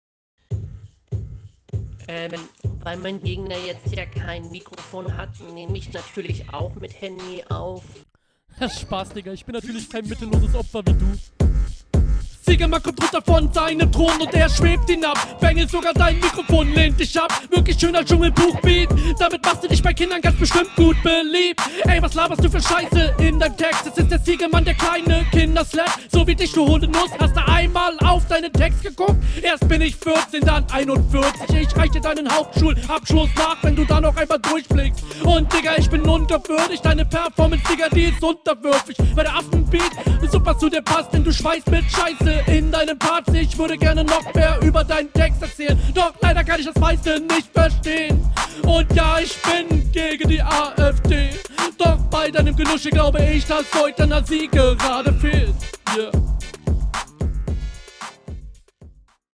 Abmische schön druckvoll.
Geiler Flow , Lines, coole Stimme, Läuft.